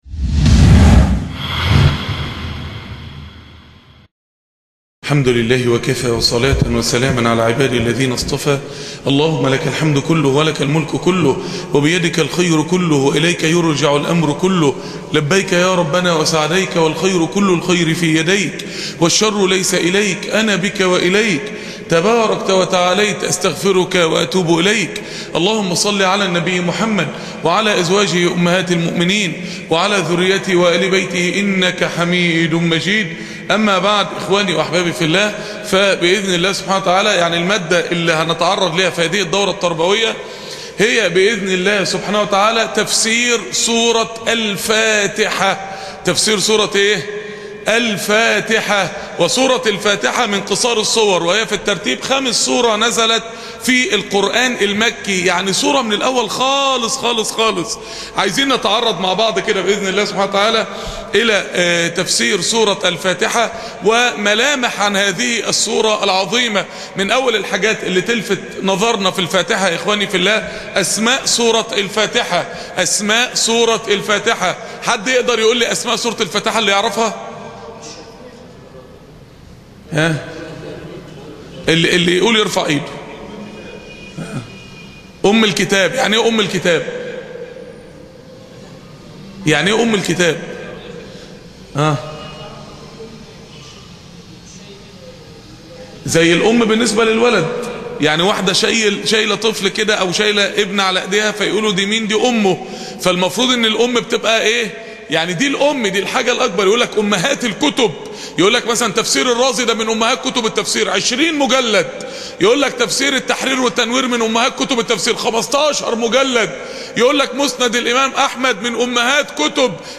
المحاضرة الأولى ( تفسير سورة الفاتحة 1